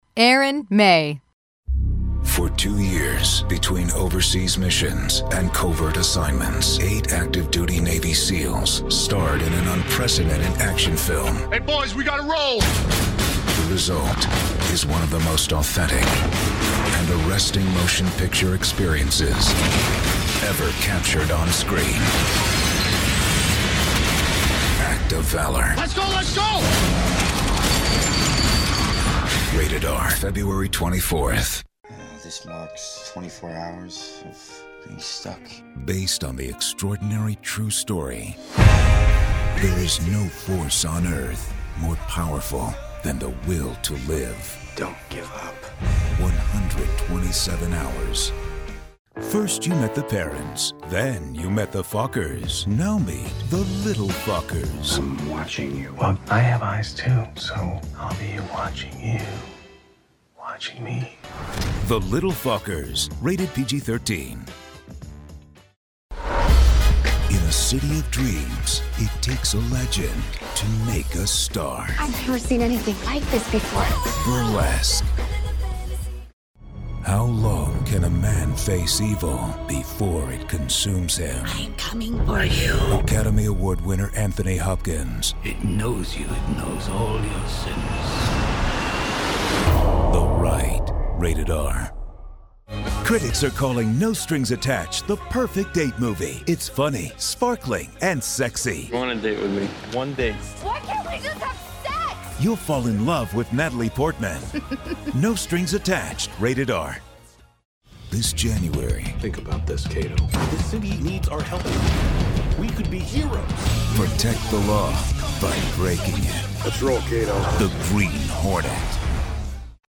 Trailer Demo